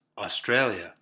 Pronunciation: / ə ˈ s t r eɪ l i ə , ɒ -/
En-Australia.oga.mp3